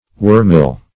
Wormil \Wor"mil\, n. [Cf. 1st Warble.]
wormil.mp3